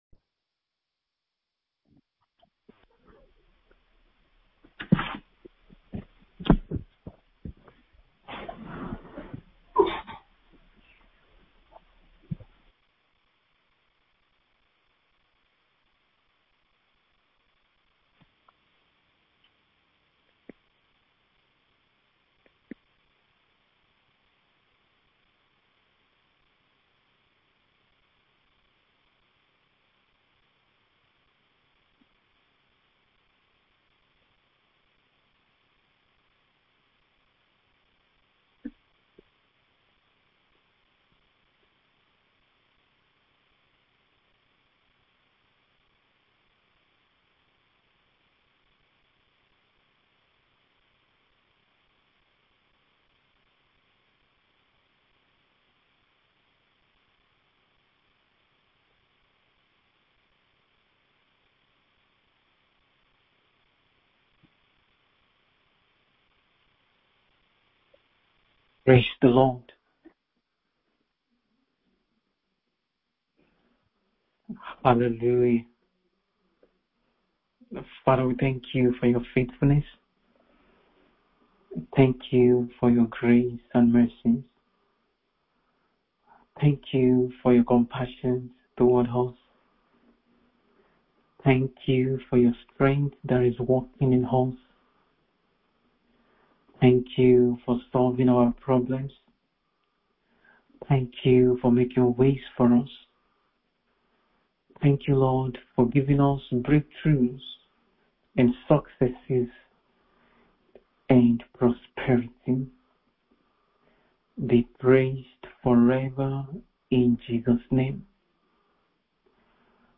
MIDNIGHT PRAYER SESSION : 23 JANUARY 2025